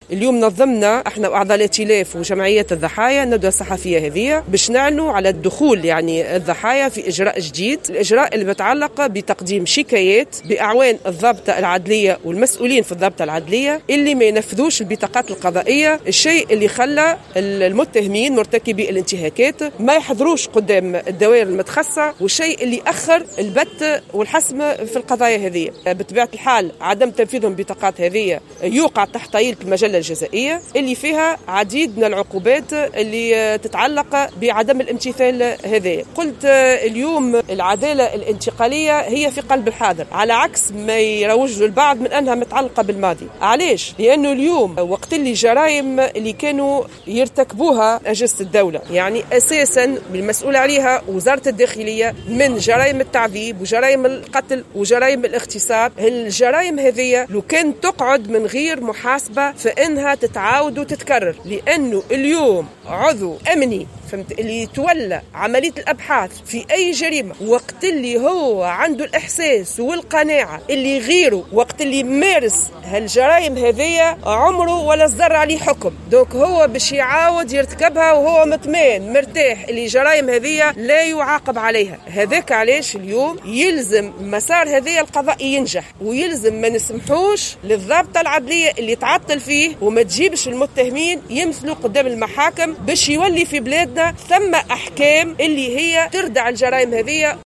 ولاحظت القرافي خلال ندوة صحفية للمنظمة الدولية لمناهضة التعذيب والإئتلاف المدني للدفاع عن العدالة الانتقالية وبمشاركة عائلات الضحايا، أنّ العدالة الانتقالية لا تتعلق بالماضي فحسب وإنما بالحاضر أيضا، بالنظر إلى أنّ عدم المحاسبة على الجرائم التي ارتكبتها أجهزة الدولة أساسا والمسؤولة عنها وزارة الداخلية، كجرائم التعذيب والقتل والاغتصاب، سيؤدّي إلى تكرارها.